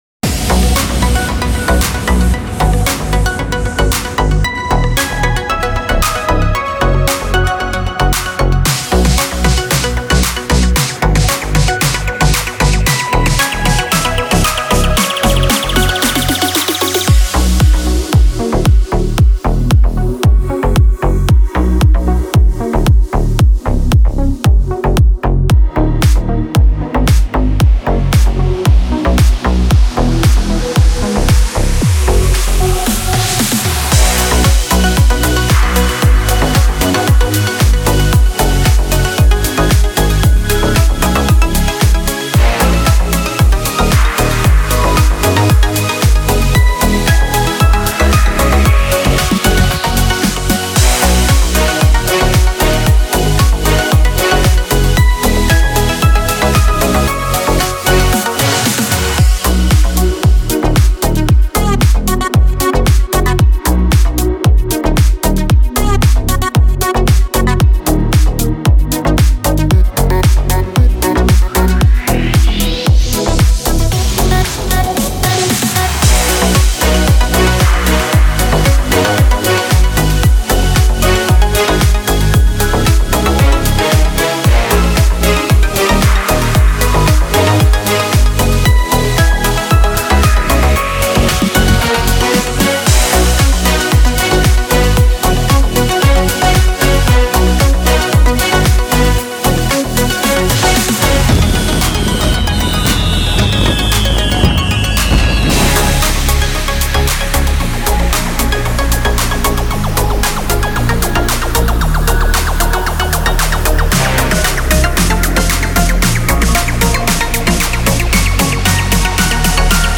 Характер песни: весёлый.
Темп песни: быстрый.
• Минусовка